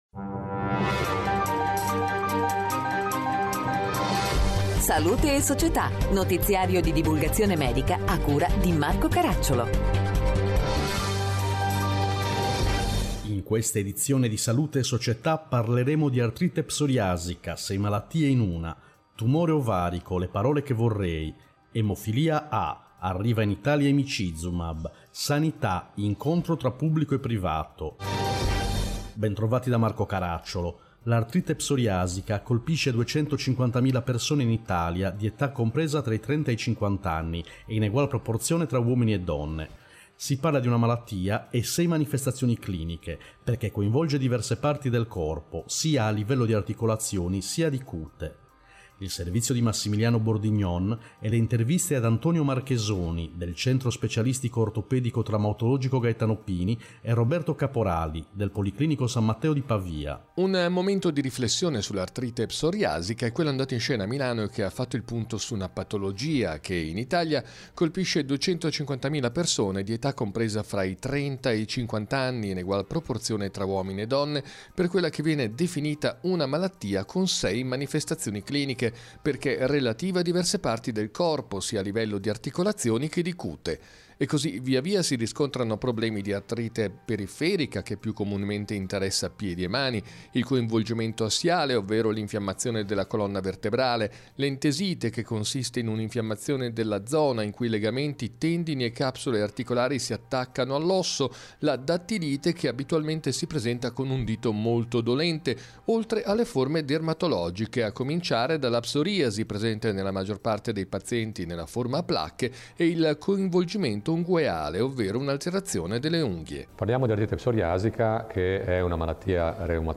In questa edizione: 1. Artrite Psoriasica, 6 malattie in una 2. Tumore Ovarico, Le parole che vorrei 3. Emofilia A, Arriva in Italia emicizumab 4. Sanità, Incontro tra Pubblico e Privato Interviste